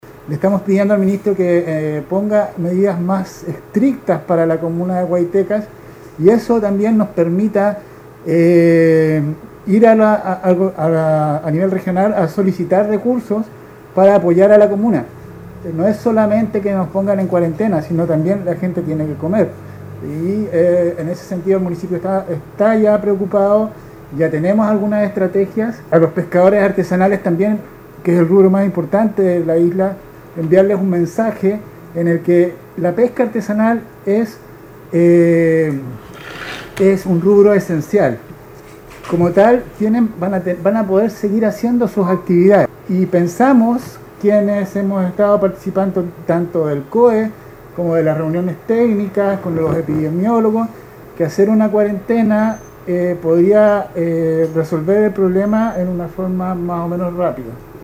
06-ALCALDE-GUAITECAS-2.mp3